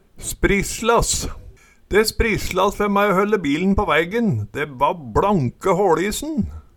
Høyr på uttala Ordklasse: Verb Attende til søk